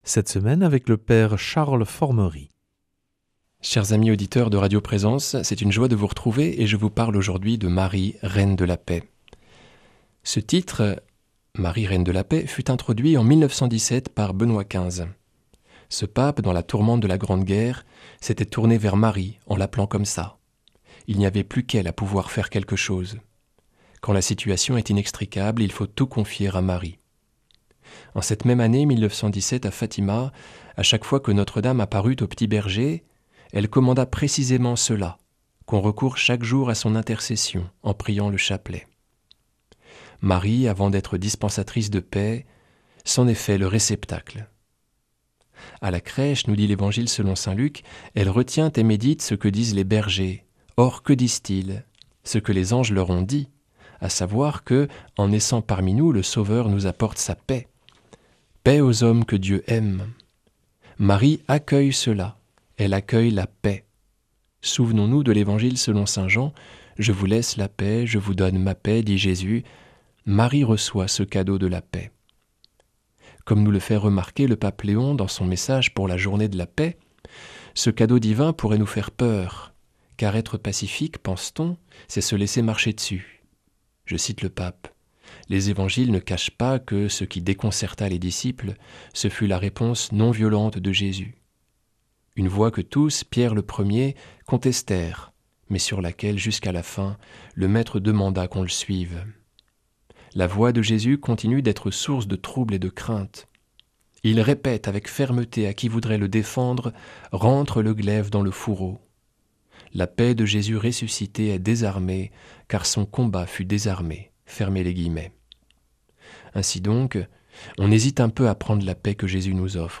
mardi 27 janvier 2026 Enseignement Marial Durée 10 min